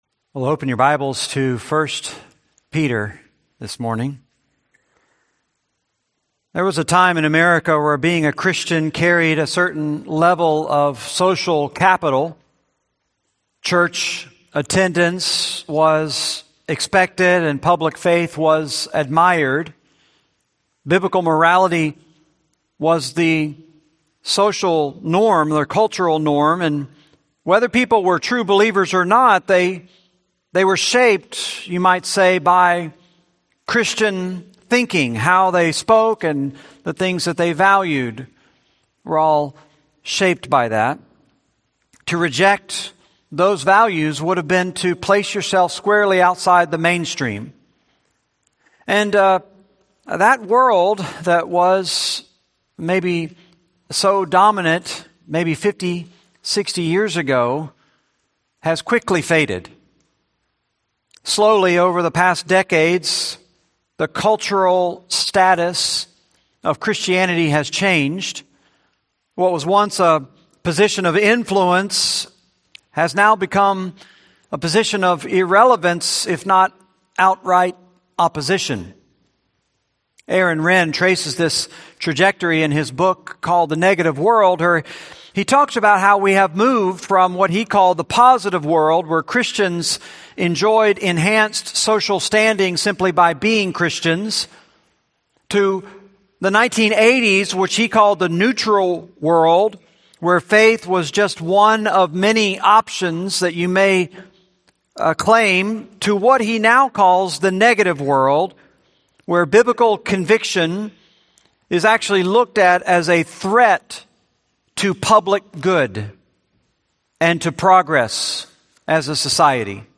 Series: 1 Peter, Sunday Sermons